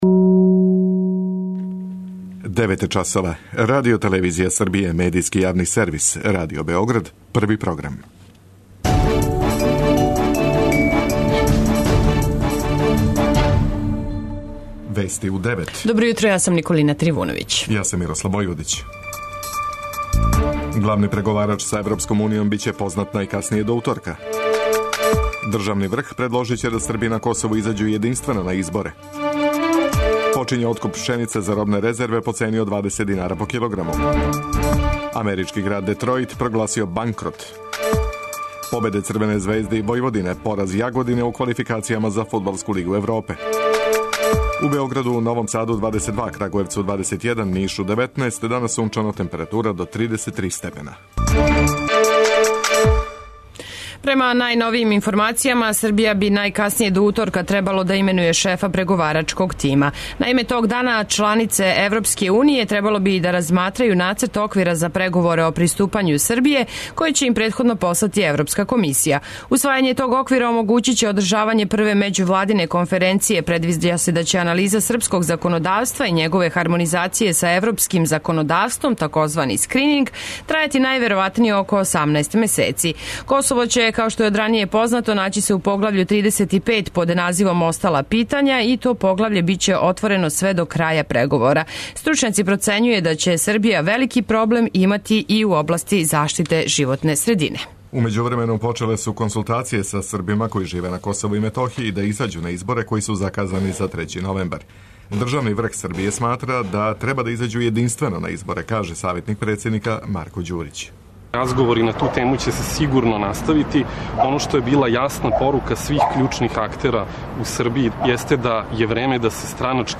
преузми : 9.99 MB Вести у 9 Autor: разни аутори Преглед најважнијиx информација из земље из света.